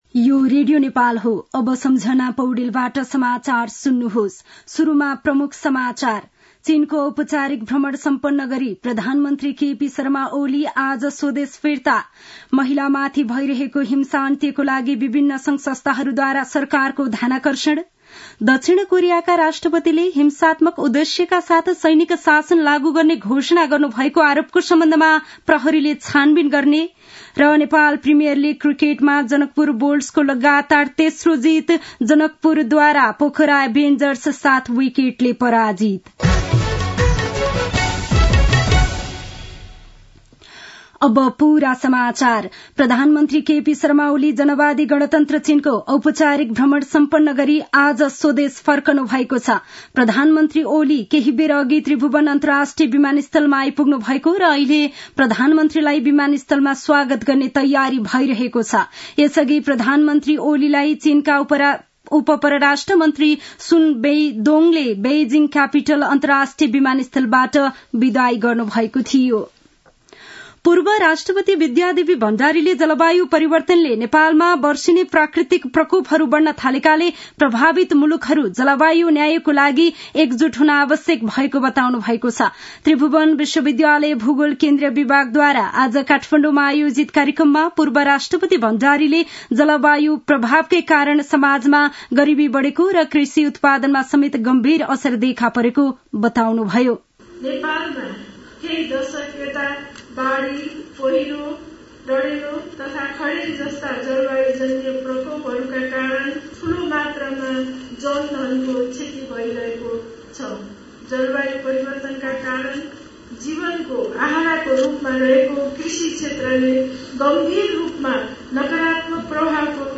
दिउँसो ३ बजेको नेपाली समाचार : २१ मंसिर , २०८१
3-pm-nepali-news-1-4.mp3